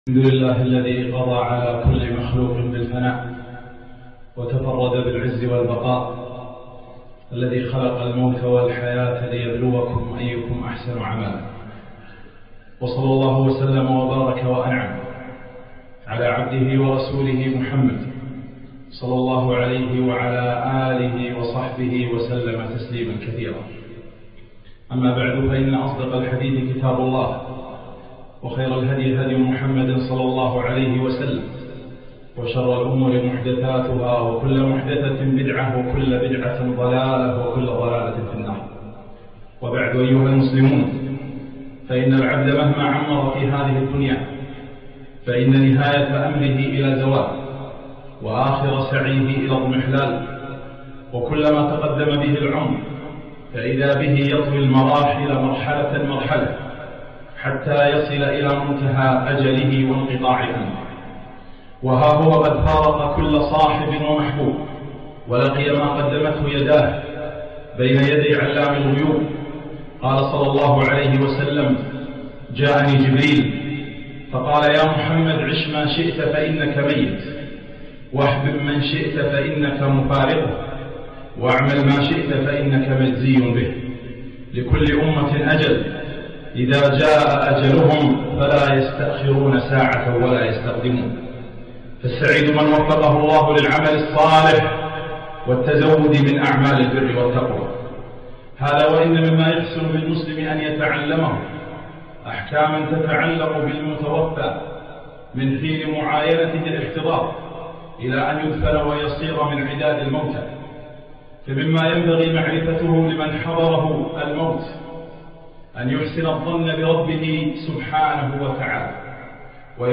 محاضرة - ماذا بعد الموت ؟